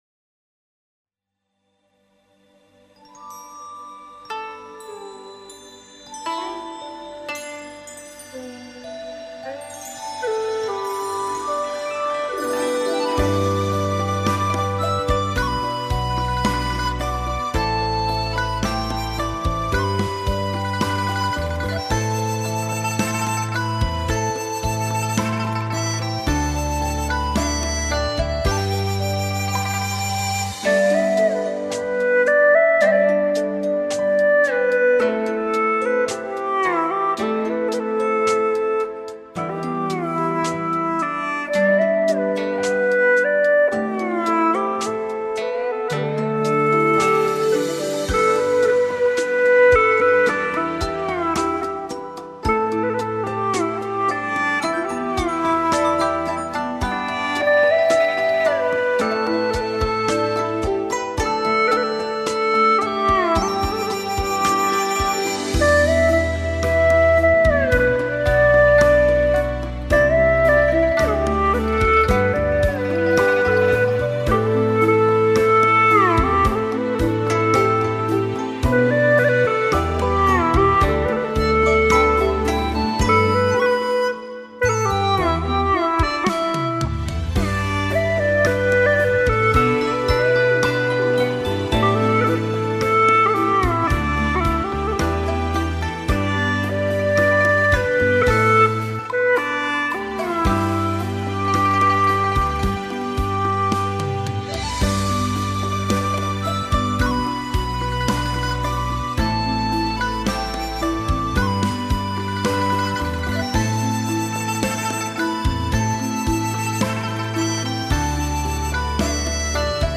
调式 : G 曲类 : 独奏